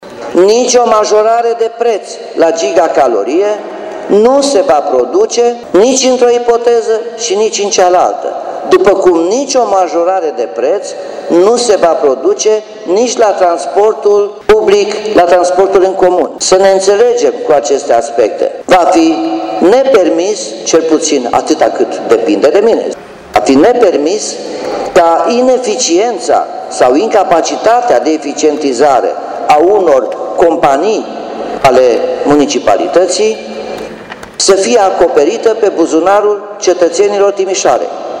Primarul Nicolae Robu admite că anularea datoriilor poate eșua, dar promite că asta nu va afecta prețul gigacaloriei.
robu-datorii-colterm.mp3